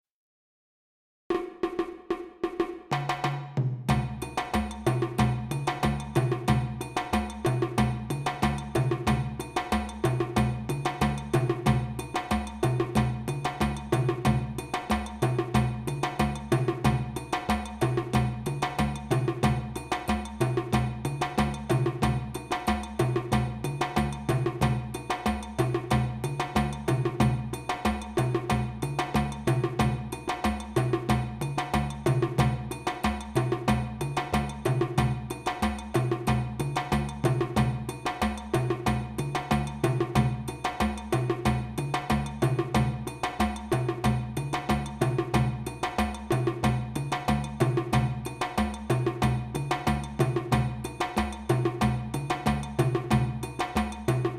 Moribayassa-lent
Moribayassa-lent.mp3